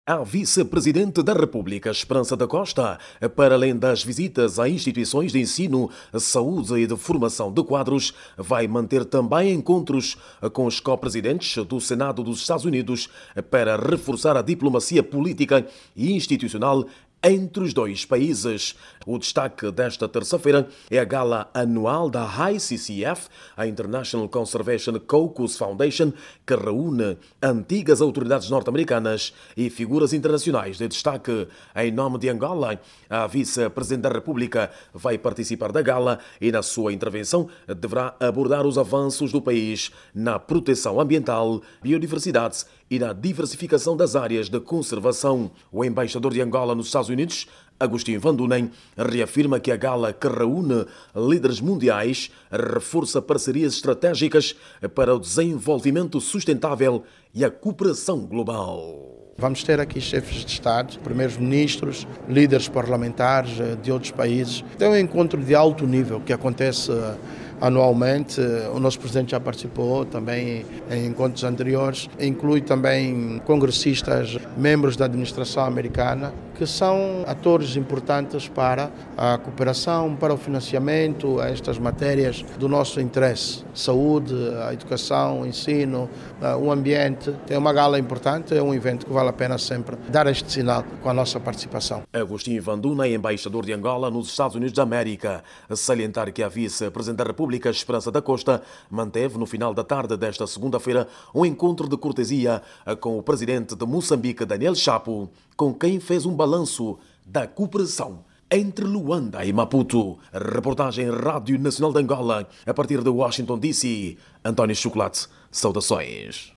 a partir de Washington, DC